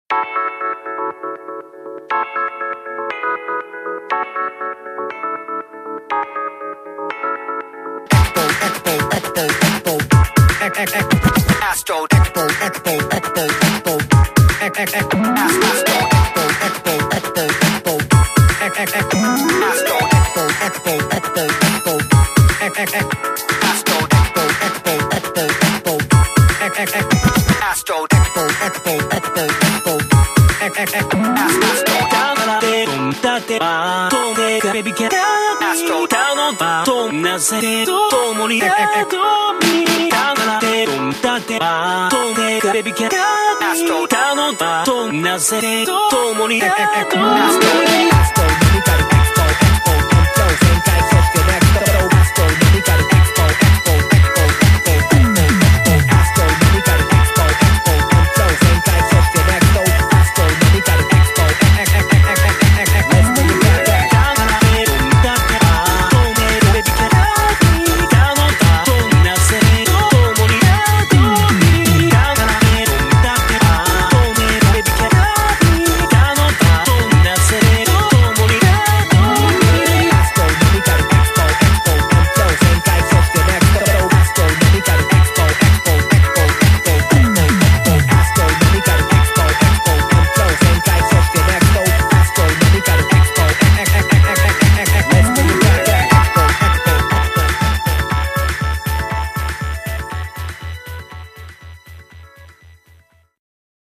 BPM60-120
Audio QualityPerfect (High Quality)
Japanese pop and electronica